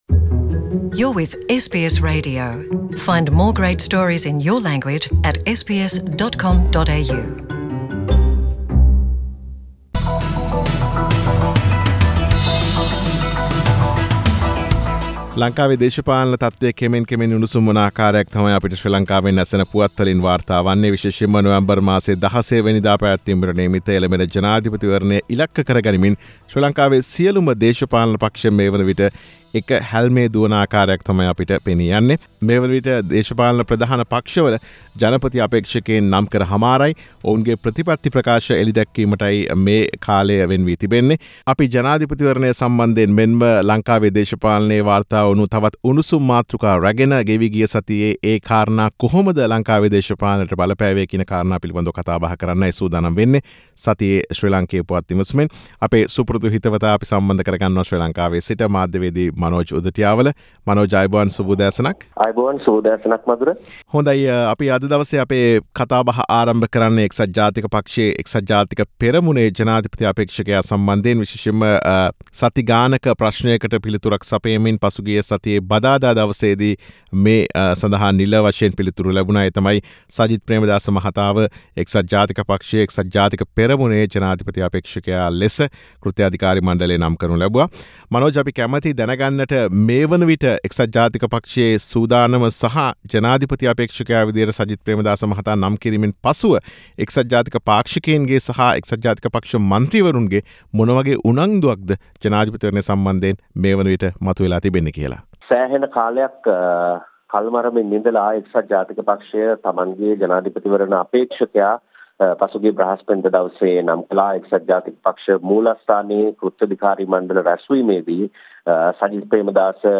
සතියේ ශ්‍රී ලාංකේය දේශපාලන පුවත් සමාලෝචනය.